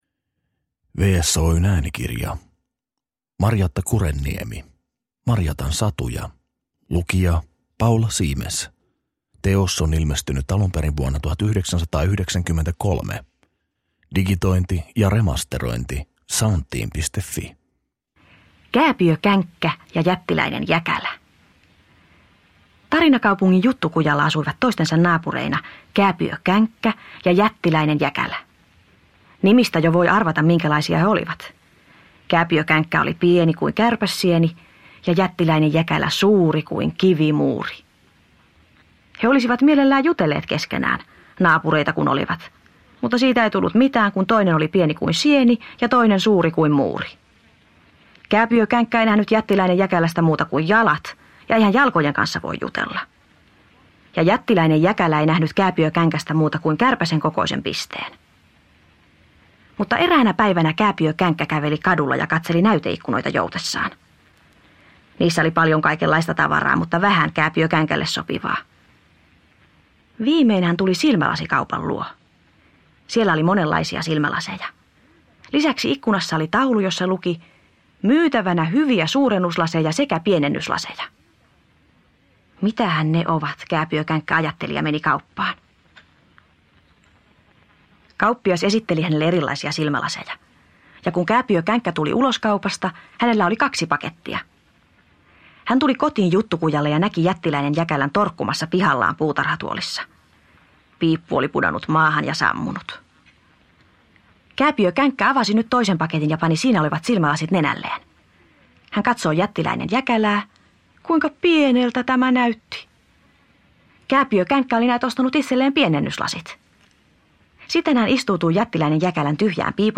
Marjatan satuja – Ljudbok – Laddas ner